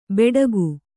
♪ beḍagu